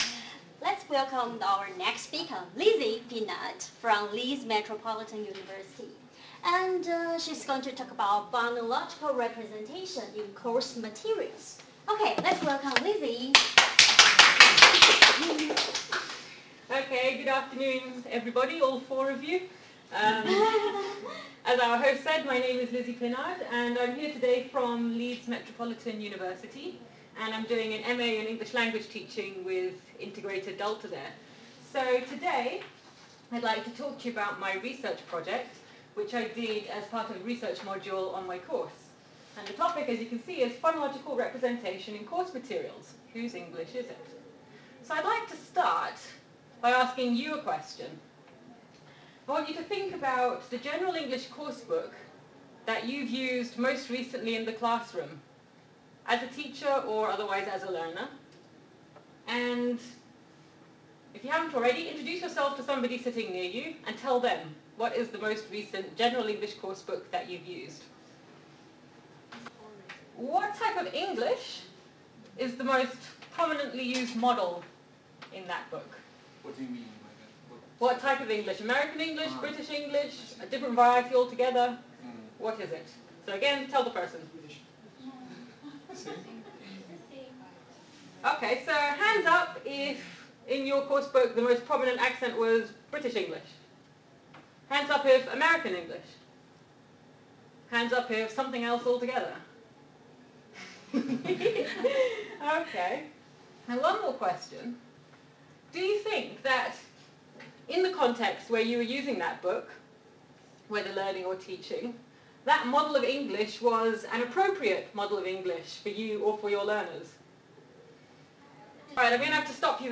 This post contains information related to the presentation I gave during the 16th Warwick International Post Graduate Conference in Applied Linguistics on 26th June 2013: